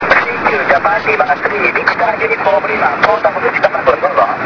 ちなみに移動地は清水市駒越海岸。
SP　SAMPLE-3　DPでの受信　(REAL AUDIO)
SAMPLE-3ではＤＰの方が強く聞こえます。